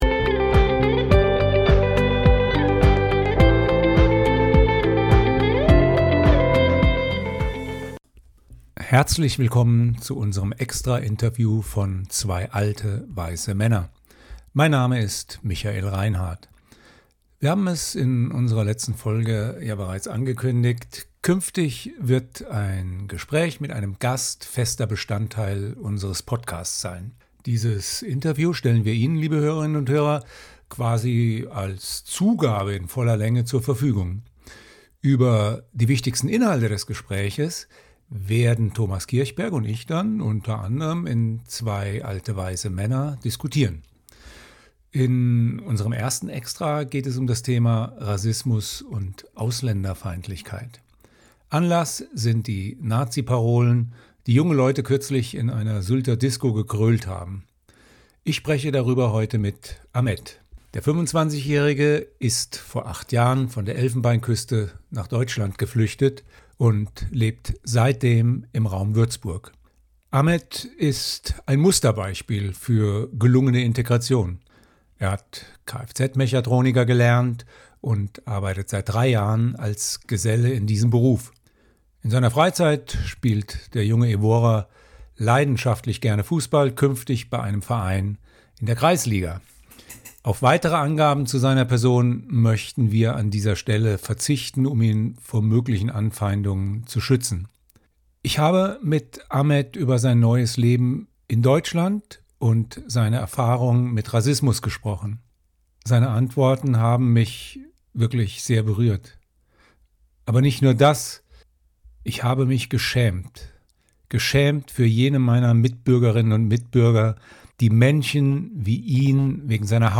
Dieses Interview stellen wir Ihnen, liebe Hörerinnen und Hörer, quasi als Zugabe in voller Länge jeweils hier auf unserem Kanal zur Verfügung.